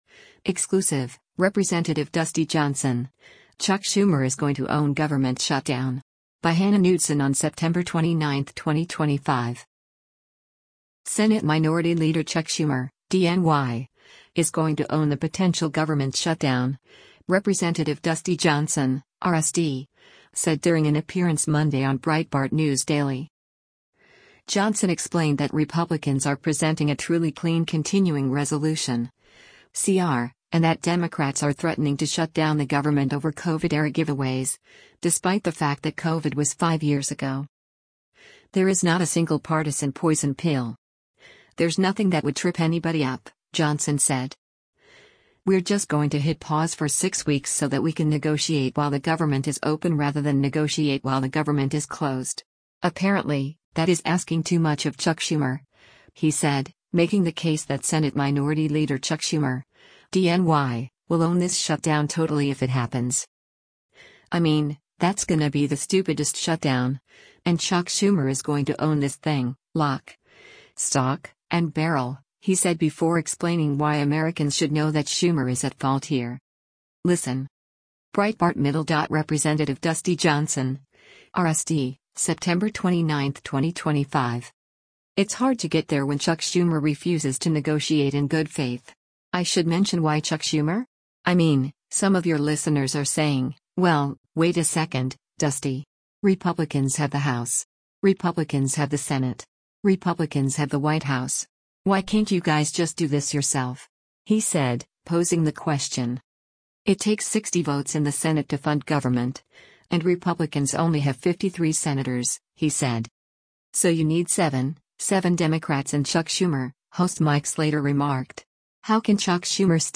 Senate Minority Leader Chuck Schumer (D-NY) “is going to own” the potential government shutdown, Rep. Dusty Johnson (R-SD) said during an appearance Monday on Breitbart News Daily.
Breitbart News Daily airs on SiriusXM Patriot 125 from 6:00 a.m. to 9:00 a.m. Eastern.